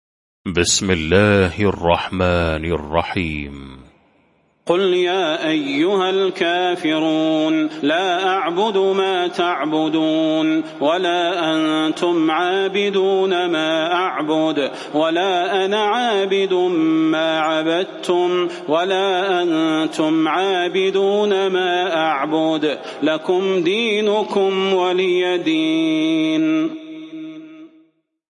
المكان: المسجد النبوي الشيخ: فضيلة الشيخ د. صلاح بن محمد البدير فضيلة الشيخ د. صلاح بن محمد البدير الكافرون